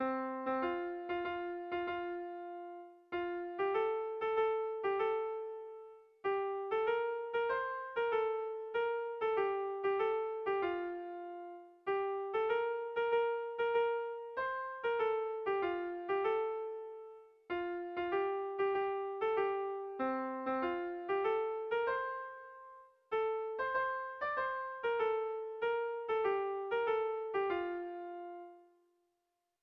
Tragikoa
ABDE